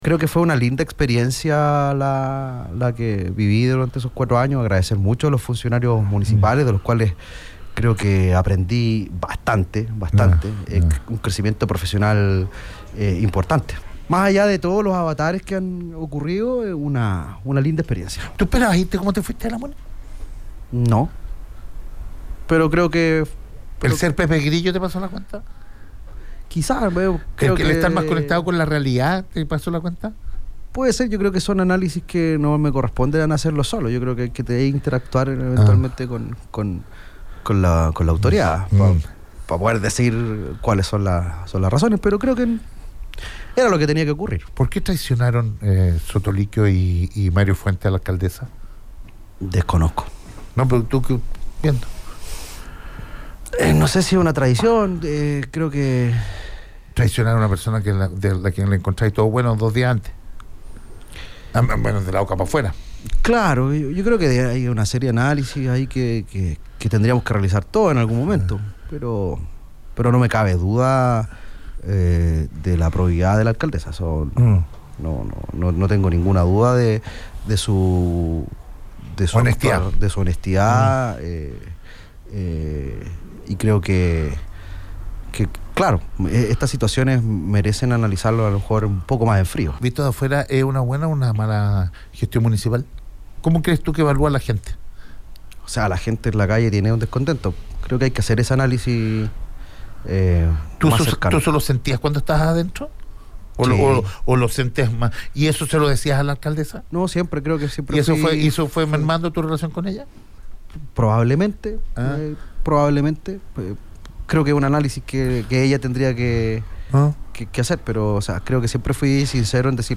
La siguiente conversación fue casual y sin previo acuerdo y aviso, aunque los conspiradores de siempre no lo crean.